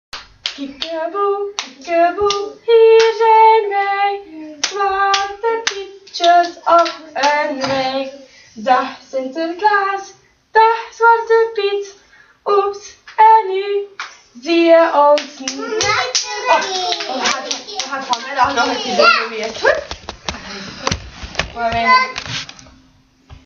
Categorie:liedjes en versjes